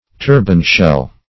Search Result for " turban-shell" : The Collaborative International Dictionary of English v.0.48: Turban-shell \Tur"ban-shell`\, n. (Zool.)